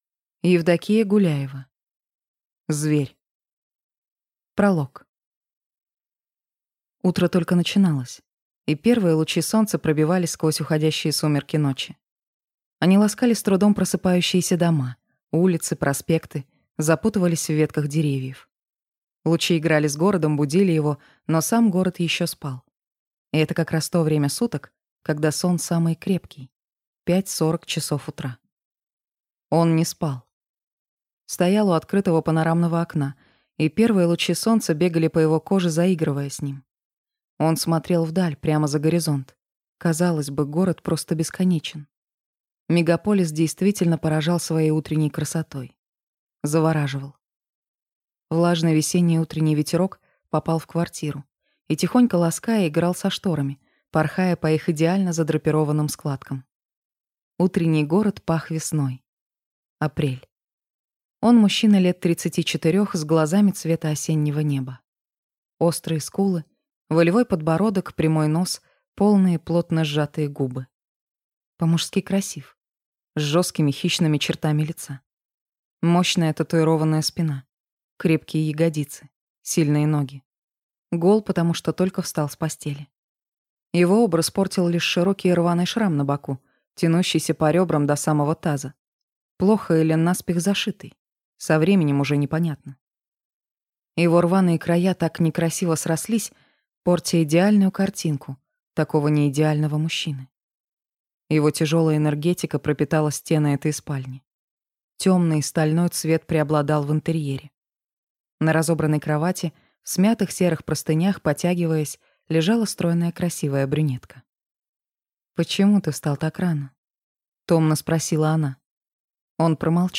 Аудиокнига Зверь | Библиотека аудиокниг